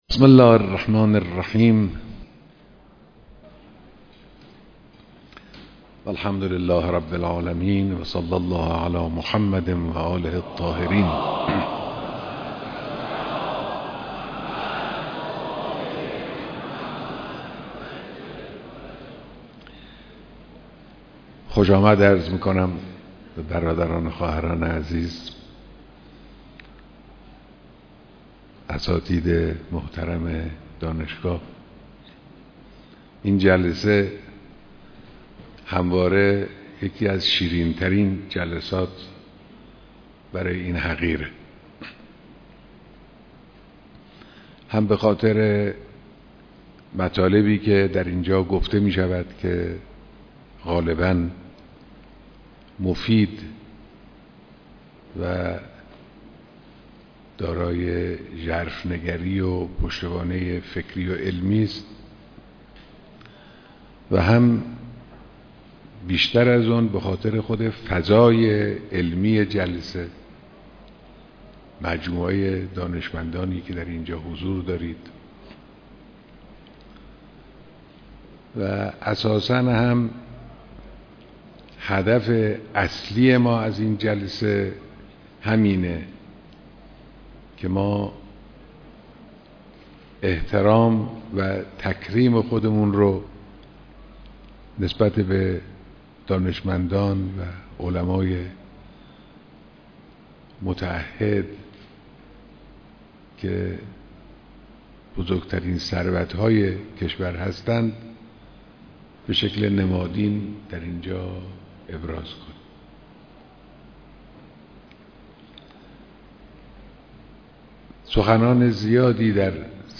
بیانات در دیدار اساتید دانشگاه‌ها